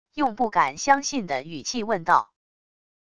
用不敢相信的语气问道wav音频